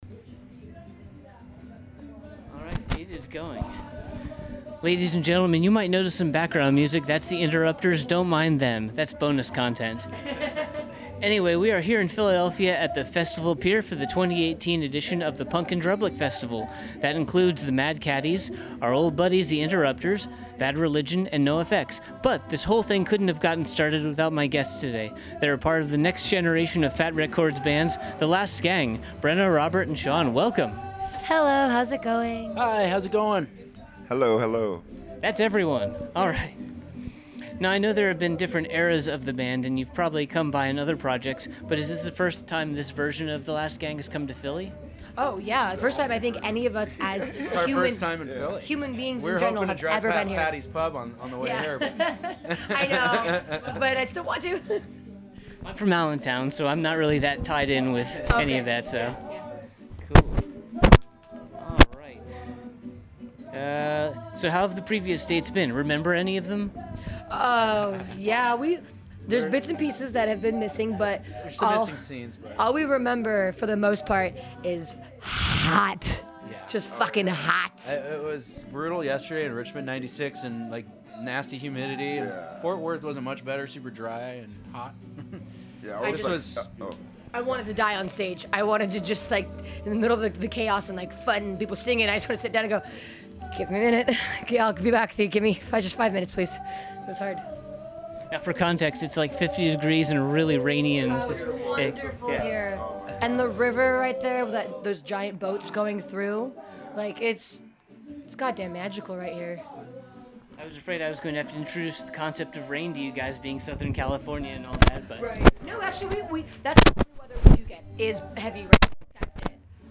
83-interview-the-last-gang.mp3